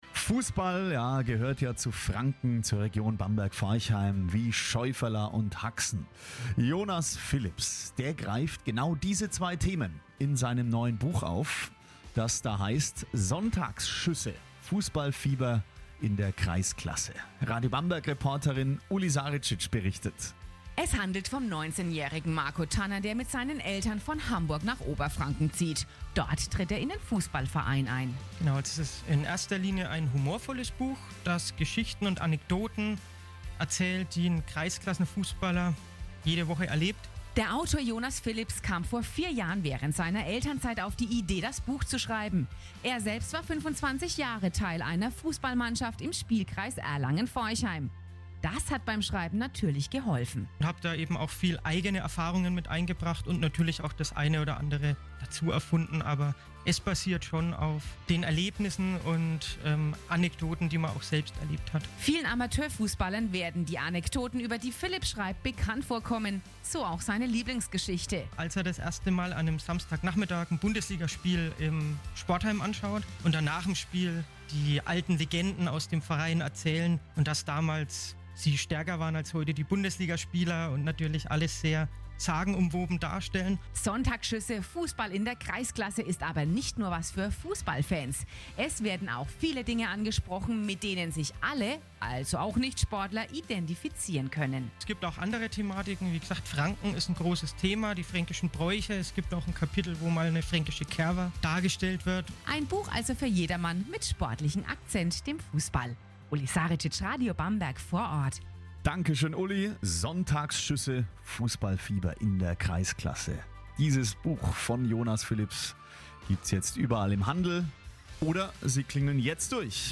Buchvorstellung und Gewinnspiel bei Radio Bamberg (Radio Bamberg, 22.08.2017)